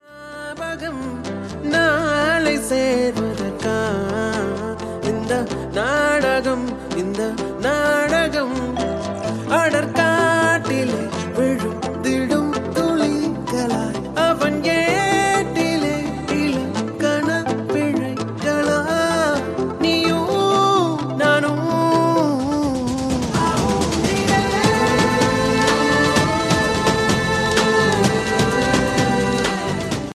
best flute ringtone download
romantic ringtone download
melody ringtone